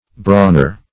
Brawner \Brawn"er\, n. A boor killed for the table.